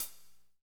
HAT P C C0HL.wav